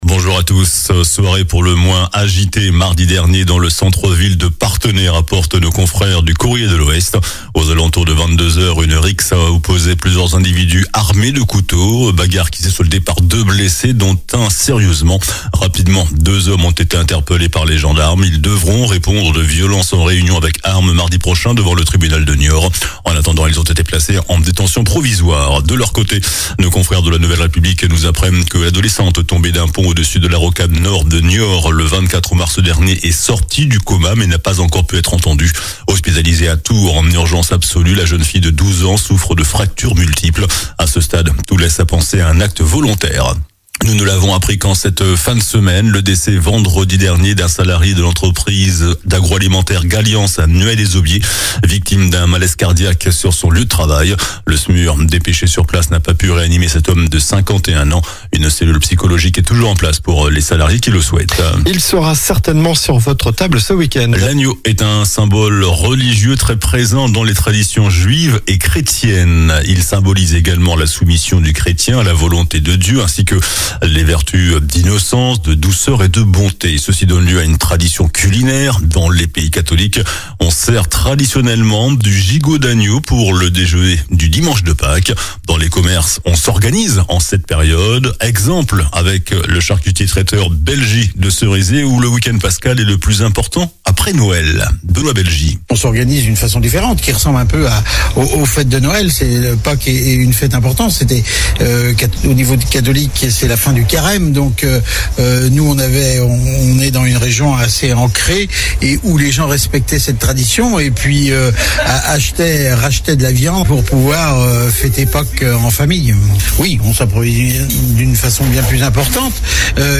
JOURNAL DU SAMEDI 19 AVRIL